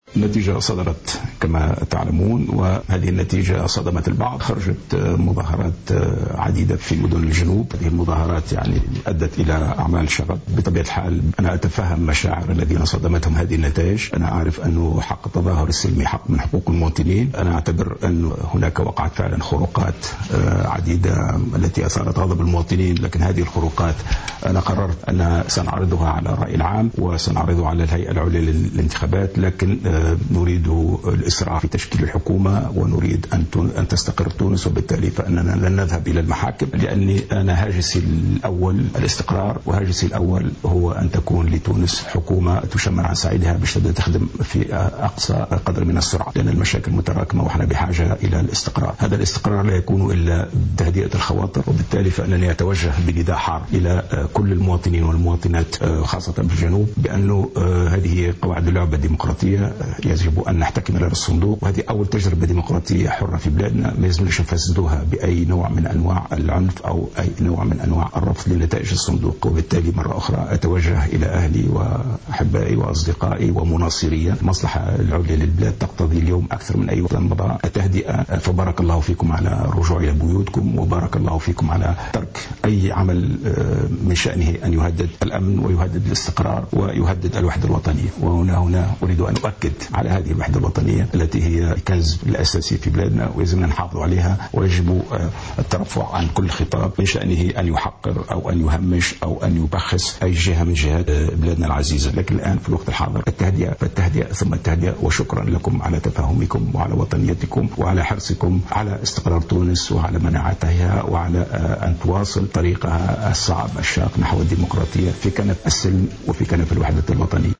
أكد الدكتور المنصف المروزقي الرئيس المنتهية ولايته في كلمة متلفزة بثتها القناة الوطنية الأولى قبوله بنتائج الإنتخابات الرئاسية وبأنه لن يتوجه للمحاكم بقصد تقديم الطعون إيمانا منه بمصلحة البلاد اللتي تحتاج في أكثر وقت مضى إلى الوحدة الوطنية